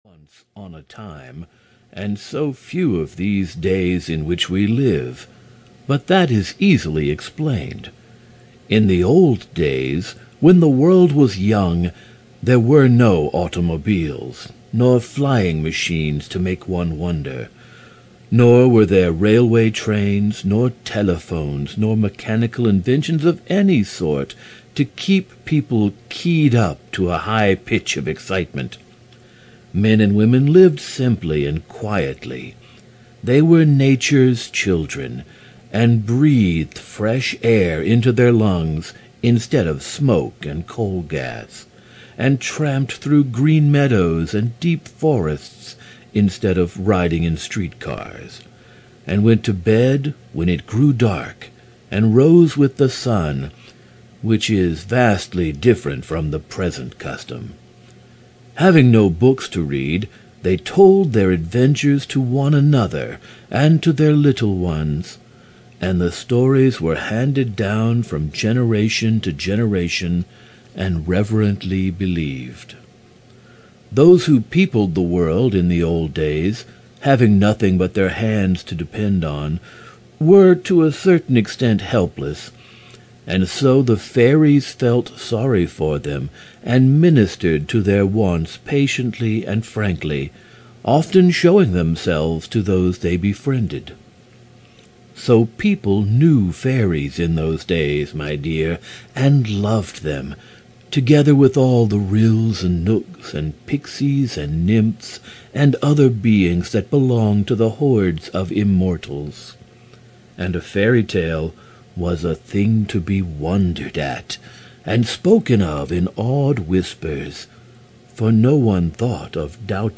The Enchanted Island of Yew (EN) audiokniha
Ukázka z knihy